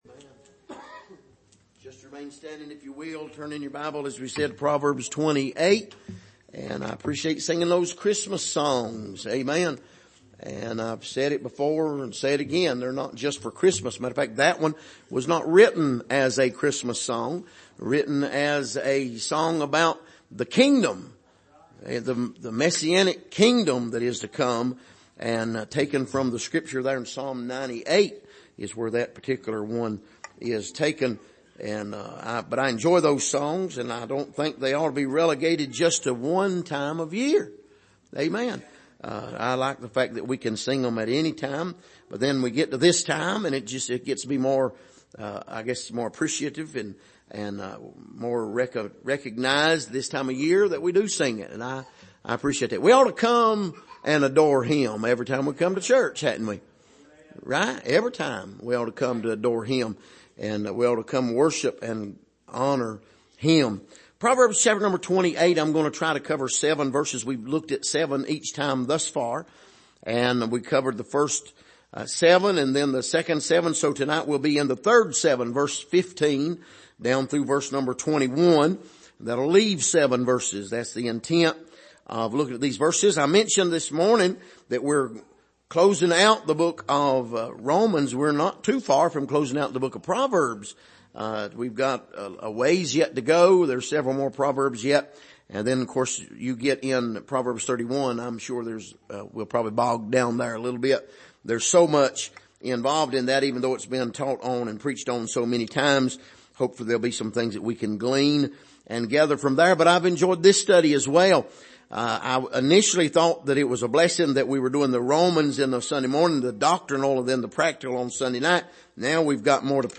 Passage: Proverbs 28:15-21 Service: Sunday Evening